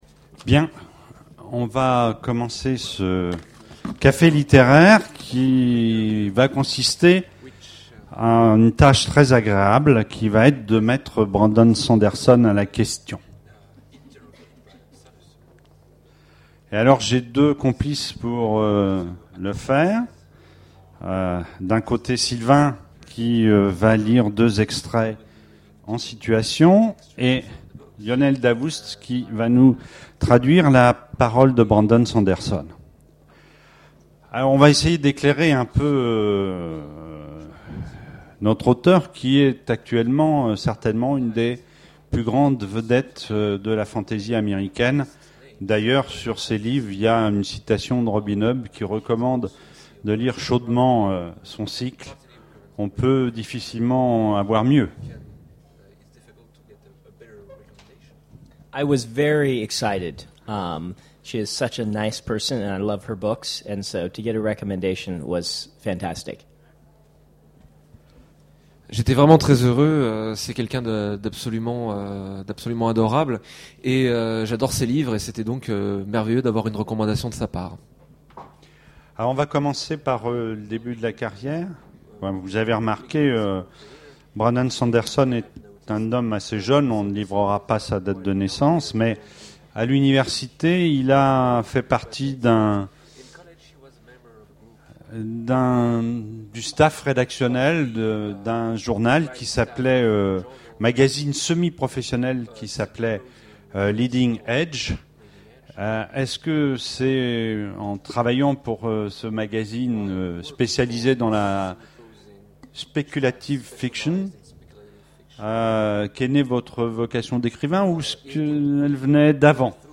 Imaginales 2011 : Conférence Rencontre avec Brandon Sanderson
Voici l'enregistrement de la rencontre avec Brandon Sanderson...
Télécharger le MP3 à lire aussi Brandon Sanderson Genres / Mots-clés Rencontre avec un auteur Conférence Partager cet article